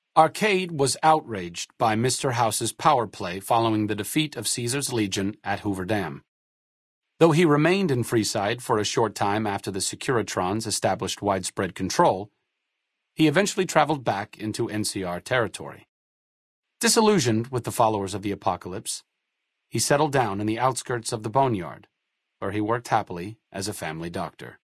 Category:Fallout: New Vegas endgame narrations Du kannst diese Datei nicht überschreiben. Dateiverwendung Die folgenden 2 Seiten verwenden diese Datei: Arcade Gannon Enden (Fallout: New Vegas) Metadaten Diese Datei enthält weitere Informationen, die in der Regel von der Digitalkamera oder dem verwendeten Scanner stammen.